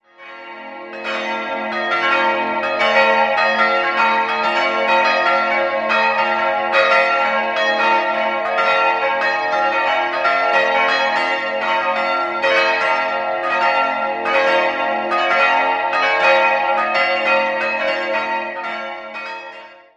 4-stimmiges ausgefülltes B-Moll-Geläute: b'-des''-es''-f'' Die beiden größeren Glocken wurden 1949 von Petit&Edelbrock in Gescher gegossen, die kleinste ergänzte Bachert im Jahr 1988.